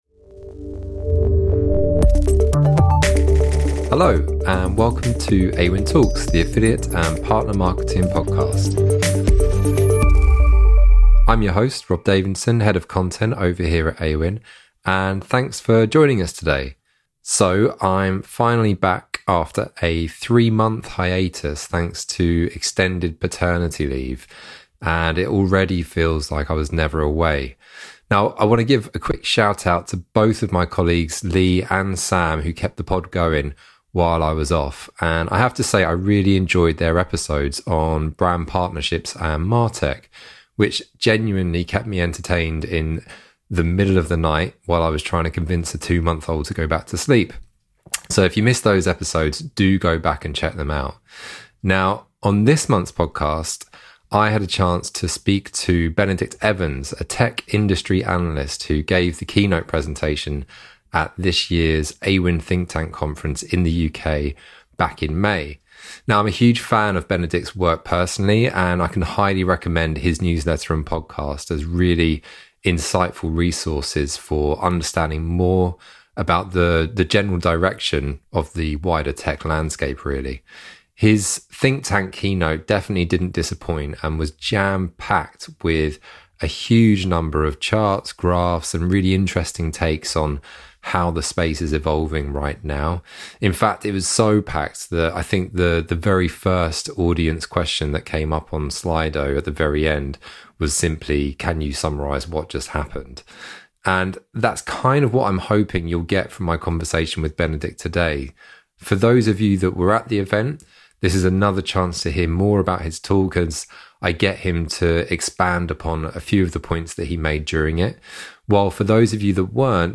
Respected tech analyst Benedict Evans joins the podcast to discuss how the internet is reshaping businesses today.